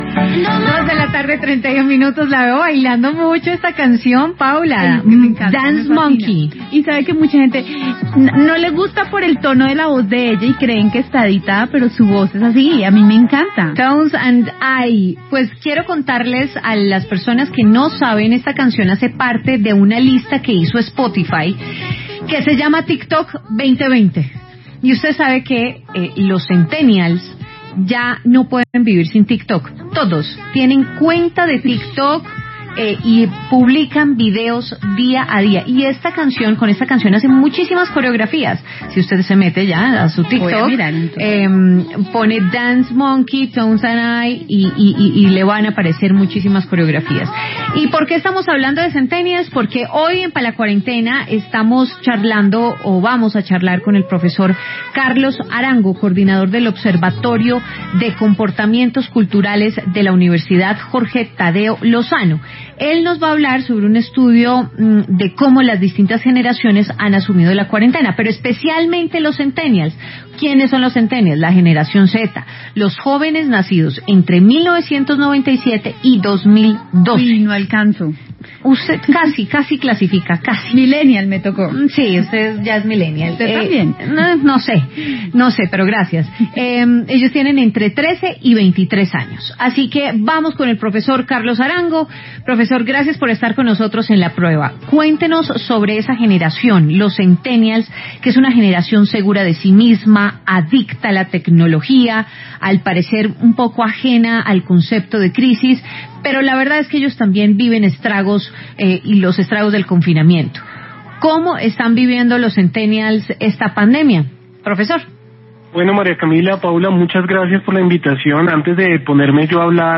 Escuche y lea aquí la entrevista en La W radio.